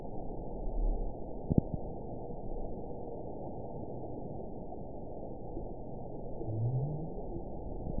event 922101 date 12/26/24 time 11:19:40 GMT (11 months, 1 week ago) score 9.41 location TSS-AB04 detected by nrw target species NRW annotations +NRW Spectrogram: Frequency (kHz) vs. Time (s) audio not available .wav